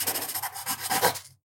sounds_pencil_write.ogg